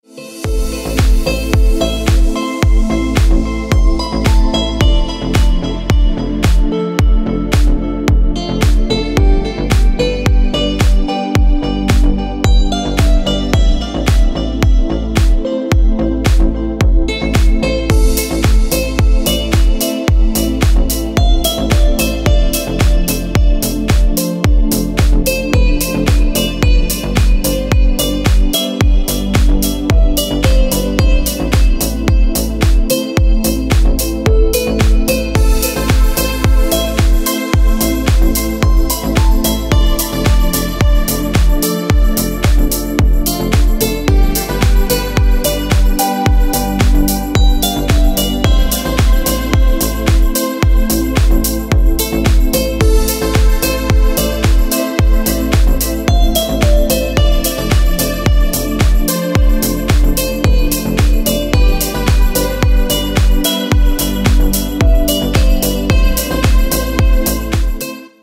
Красивые мелодии и рингтоны